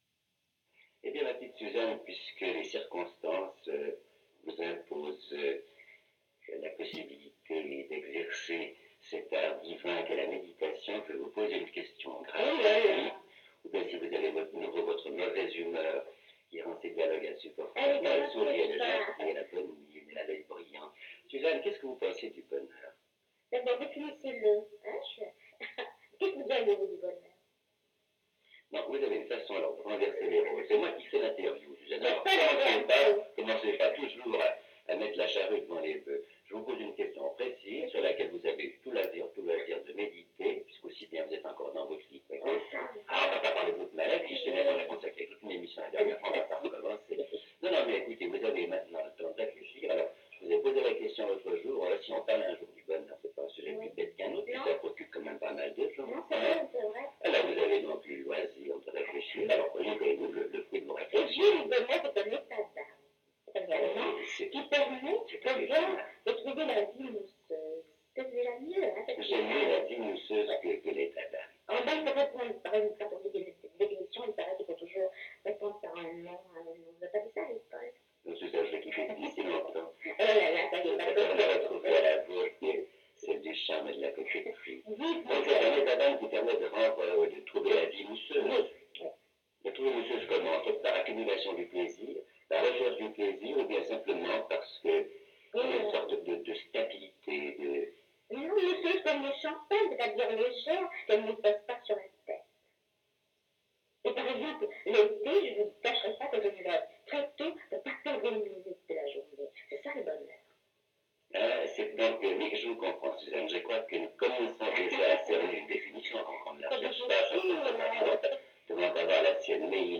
Radio romande, 28 avril 1963
Interview